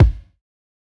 Kick (1).wav